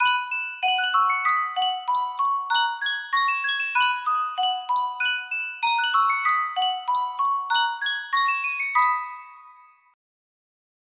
Sankyo Miniature Music Box - Silver Threads Among the Gold NGH music box melody
Full range 60